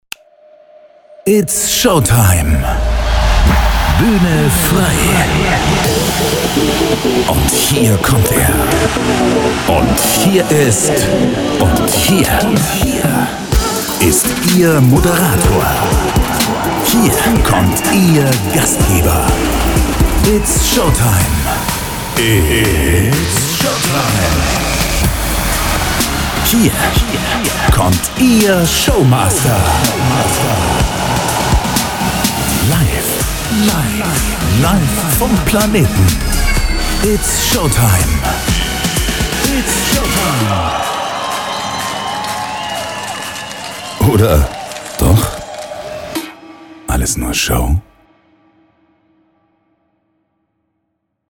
Presenter-Voice TV
Hier kommt sie: Die neue Stimme aus dem Off. Machen Sie sich bereit für einen kurzen Ausflug ins Showgeschehen – kraftvoll und dynamisch serviert.
Die Sprachaufnahmen werden professionell im eigenen Studio produziert und geschnitten – so entsteht eine Auswahl passender Varianten in höchster Qualität.